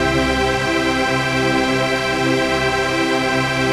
RAVEPAD 05-LR.wav